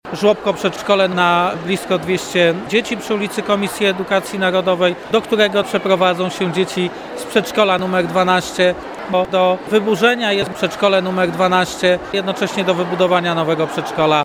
Mówił o tym prezydent Stalowej Woli Lucjusz Nadbereżny